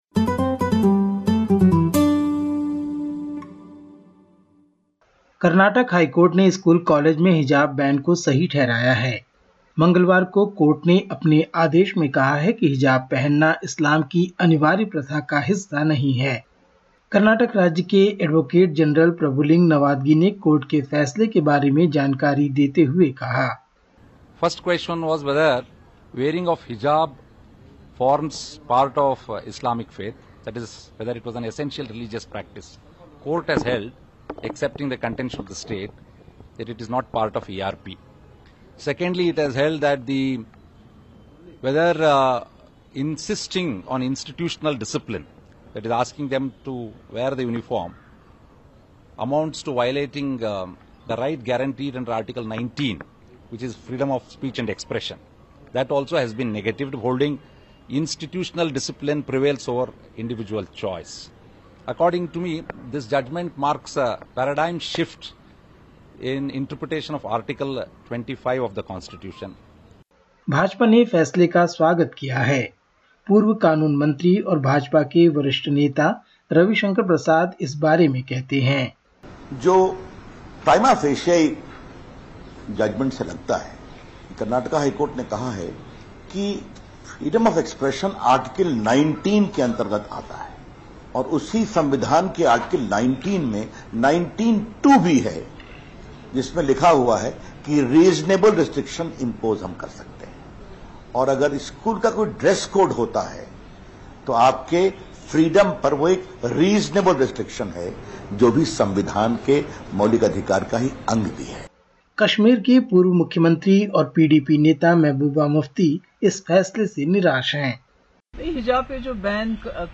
Listen to the latest SBS Hindi report from India. 16/03/2022